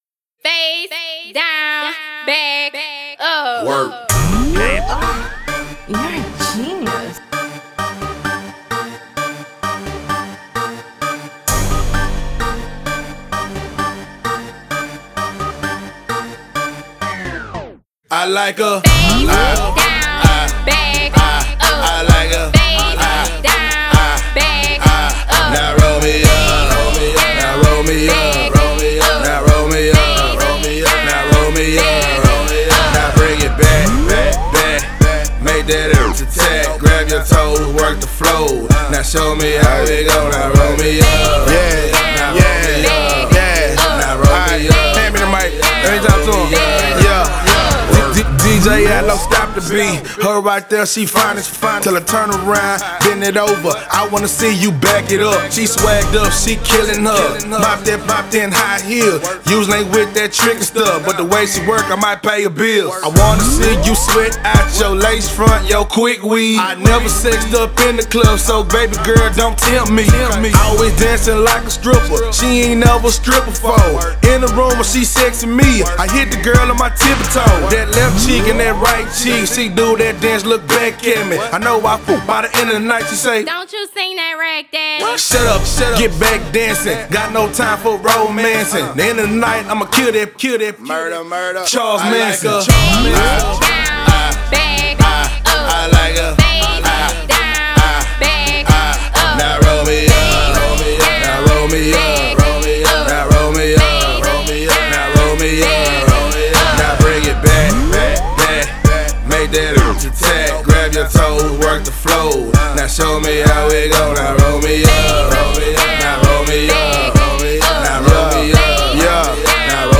NEW H TOWN BANGER